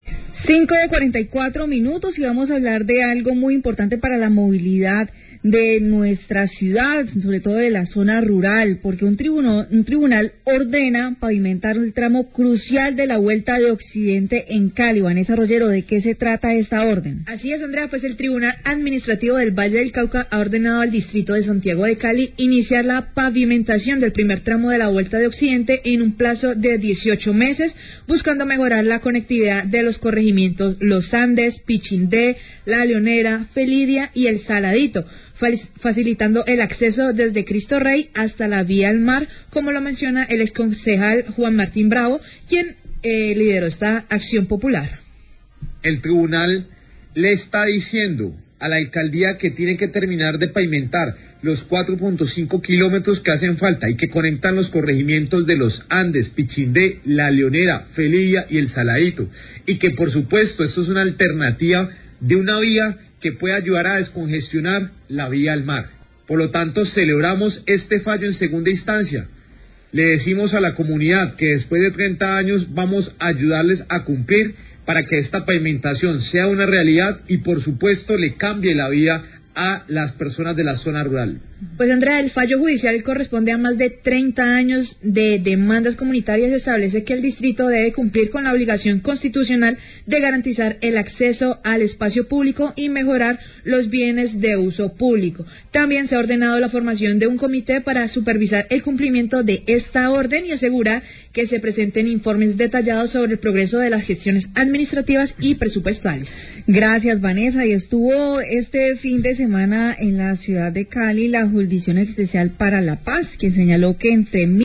Radio
Debido a una acción popular interpuesta por el exconcejal Juan Martín Bravo, quien habla del tema.